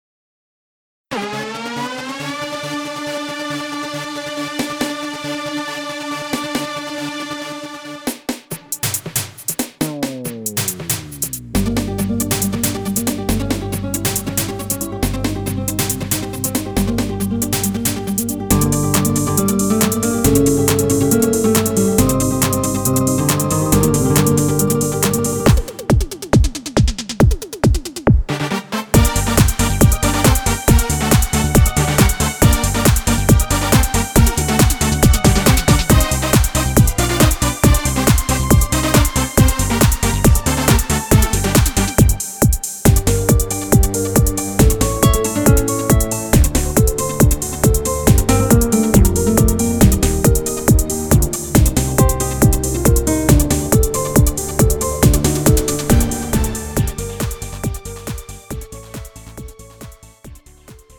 음정 (-1)
장르 가요 구분 Lite MR